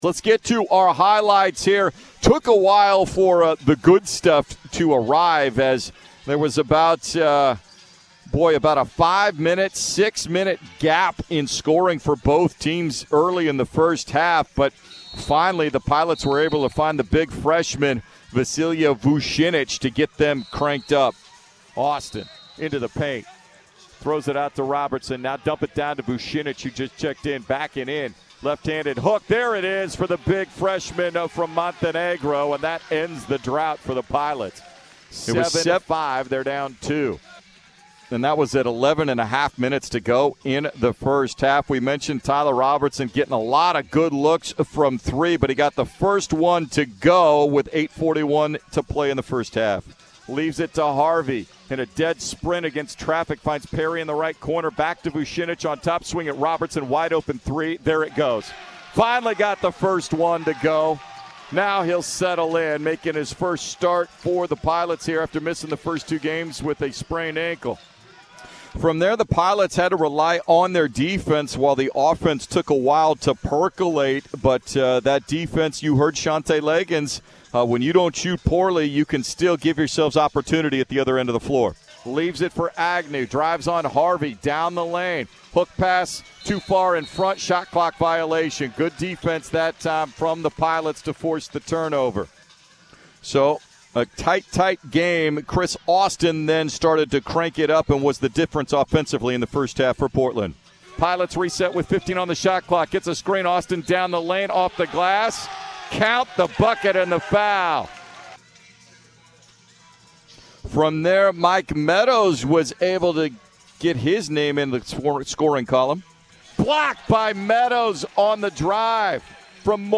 Men's Basketball Radio Highlights vs. Alcorn State